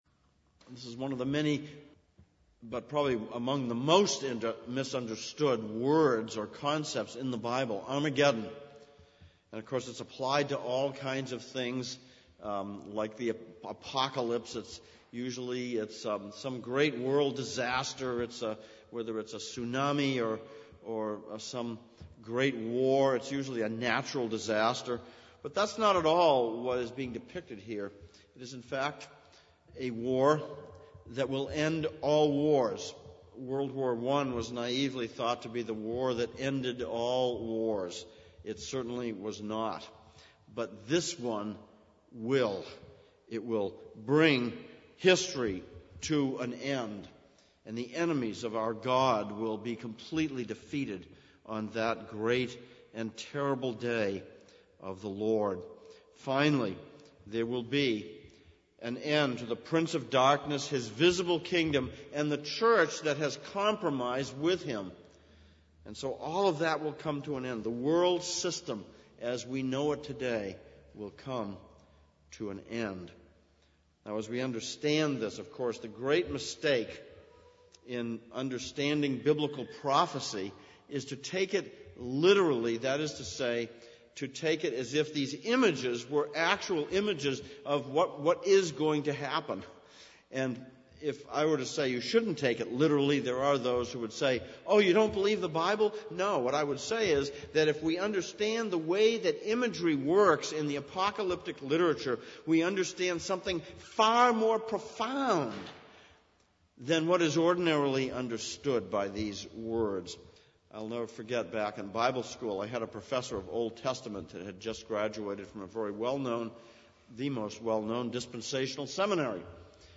Exposition of Revelation Passage: Revelation 16:10-21, Zechariah 12:1-14 Service Type: Sunday Evening « 26.